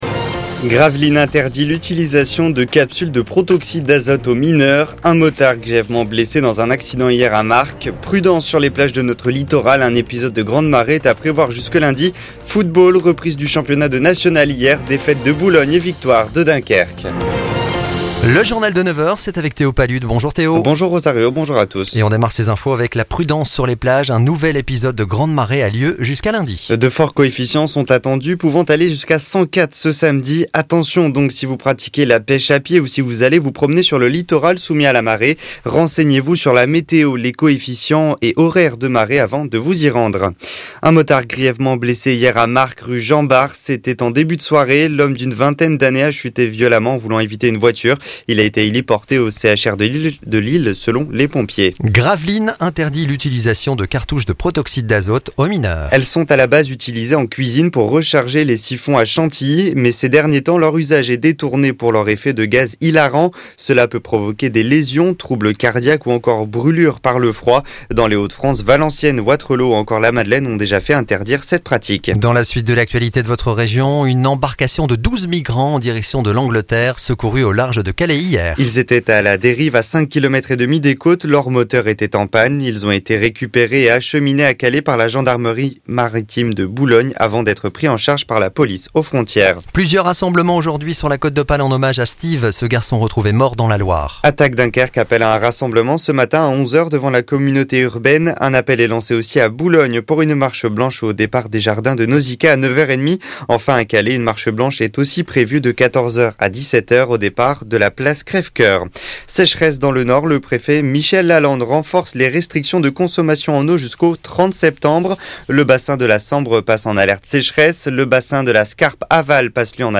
Retrouvez l'essentiel de l'actualité de la Côte d'Opale et les grands titres des infos en France et dans le monde en 6 minutes. Journal de 9h.